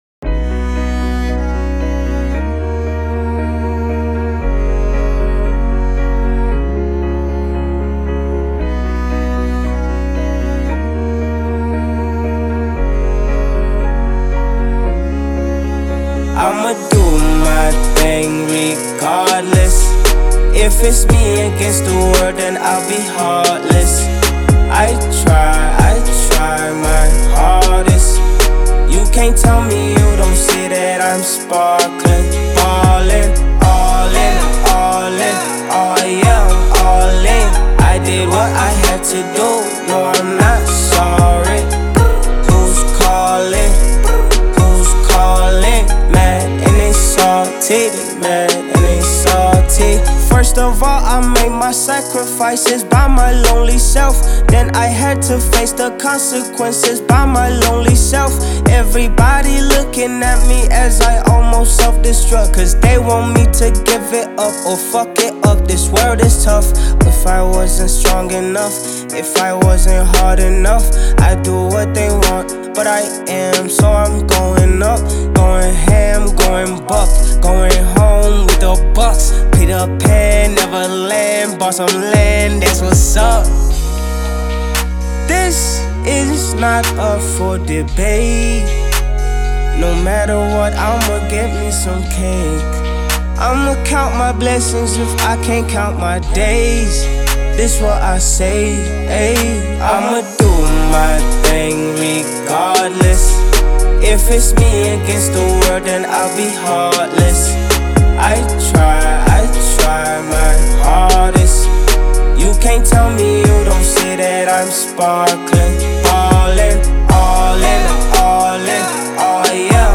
smooth Afrobeat rhythms